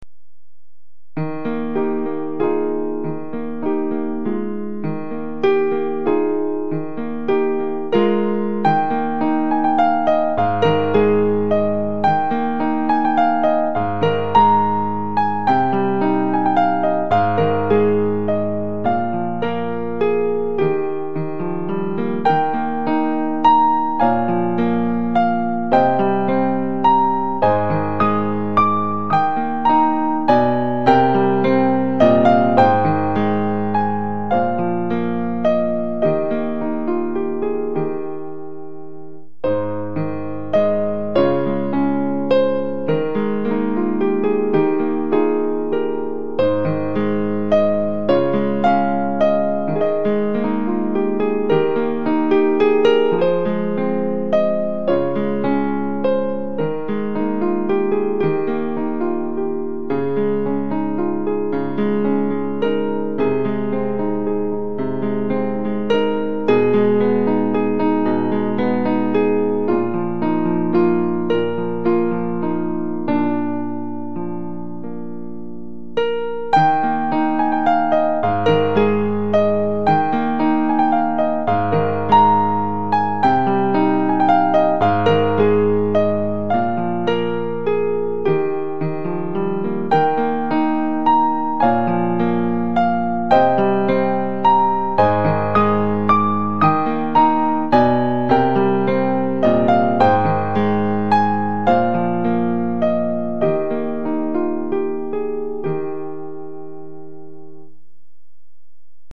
Un petit thème gentil et frais